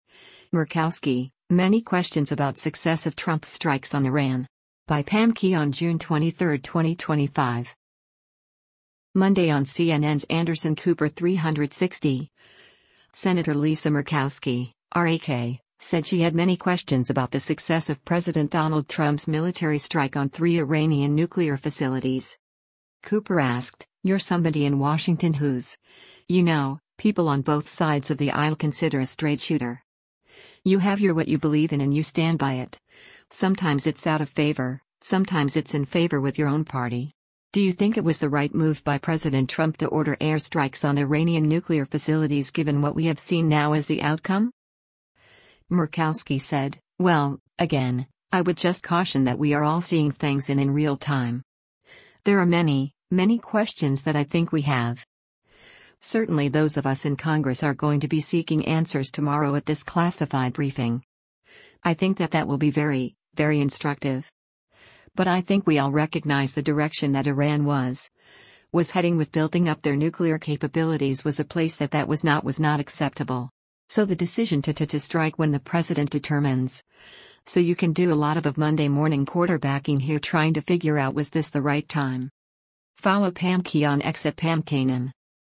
Monday on CNN’s “Anderson Cooper 360,” Sen. Lisa Murkowski (R-AK) said she had “many questions” about the success of President Donald Trump’s military strike on three Iranian nuclear facilities.